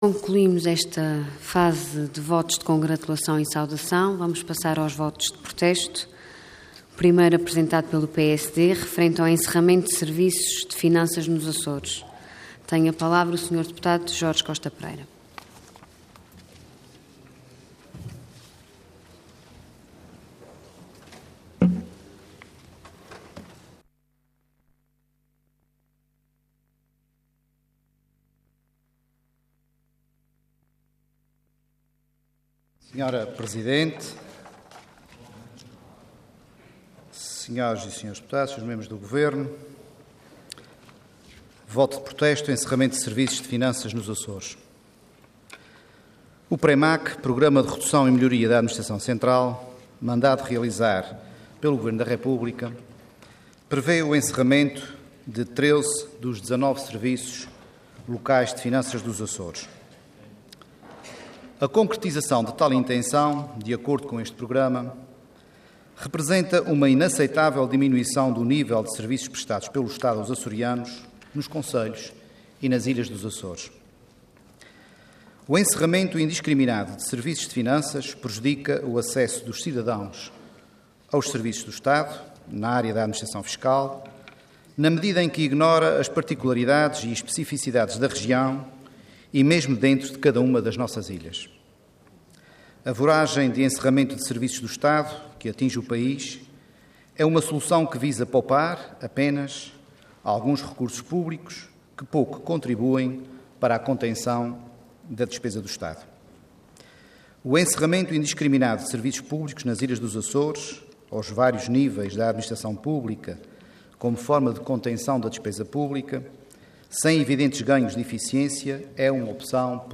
Intervenção Voto de Protesto Orador Jorge Costa Pereira Cargo Deputado Entidade PSD